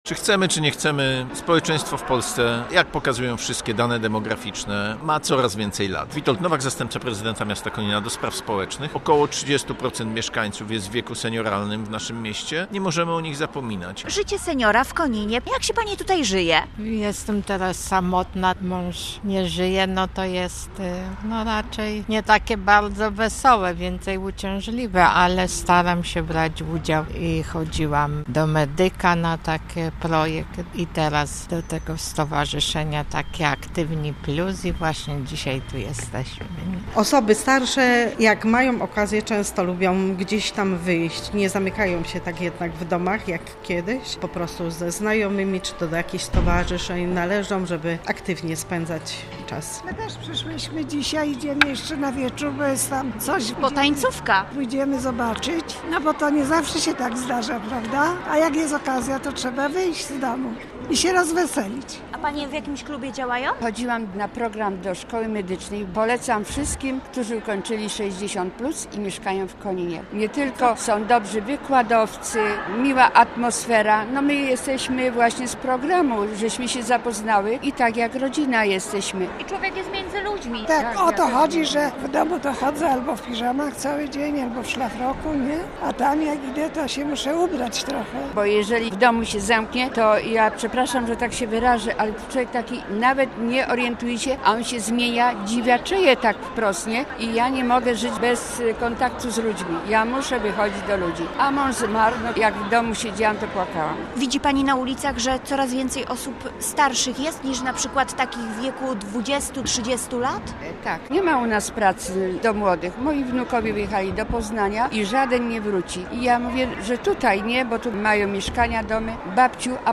- mówi zastępca prezydenta Konina Witold Nowak.
- mówią seniorki.